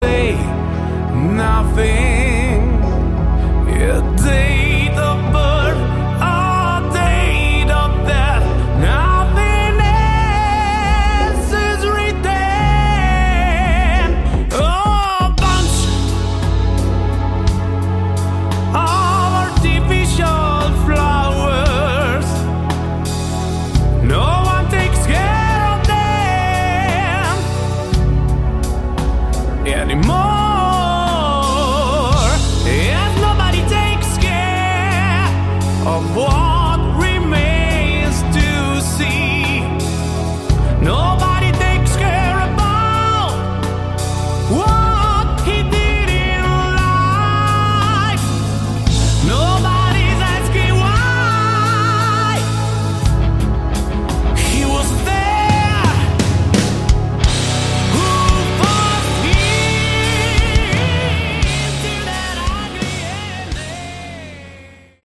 Category: Melodic Prog Rock
vocals
guitars
keyboards
bass
drums